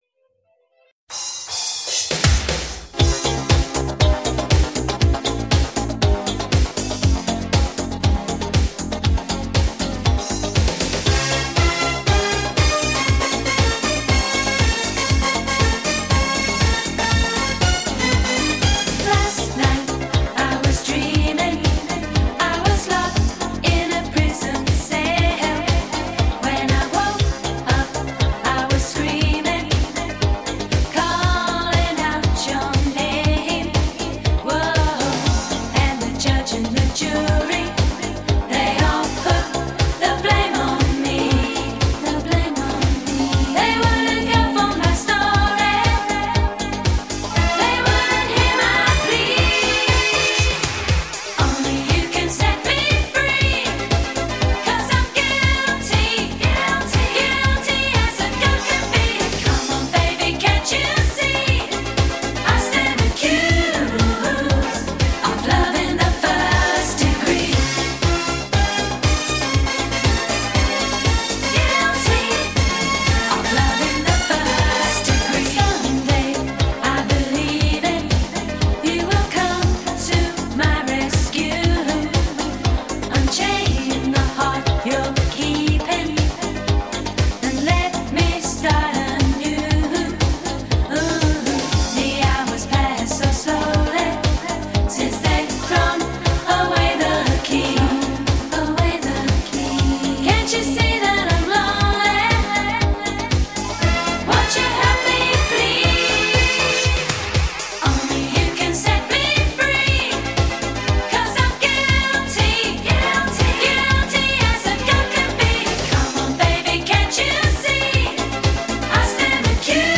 都是你很熟悉的经典迪斯科舞曲，